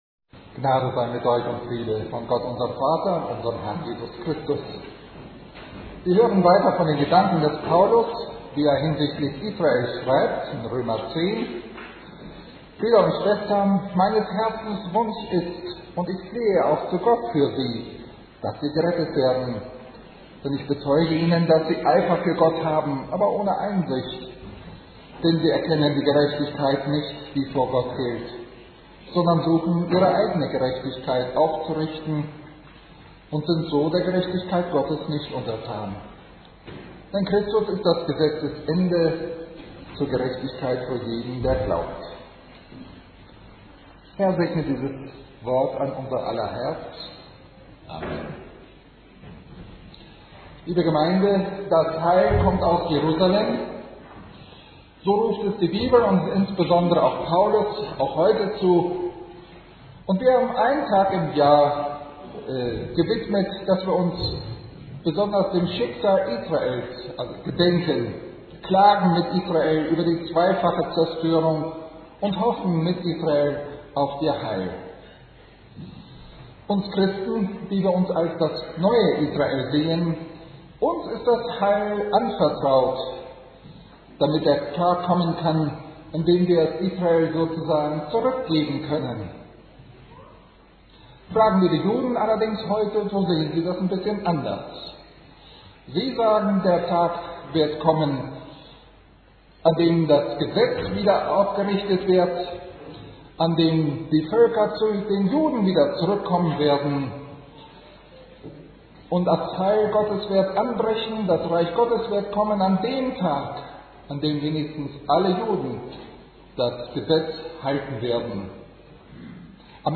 Lutherische Gemeinde Lüneburg Lutheran Congregation - Predigt Sermon 10. Sonntag nach Trinitatis (Der Herr und sein Volk) - FELSISA.